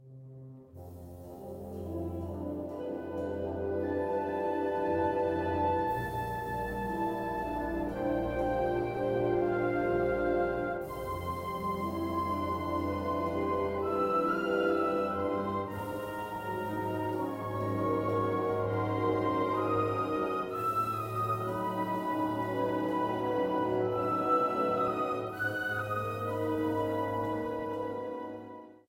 Kategorie Blasorchester/HaFaBra
Unterkategorie Zeitgenössische Originalmusik (20./21.Jhdt)
Besetzung Ha (Blasorchester)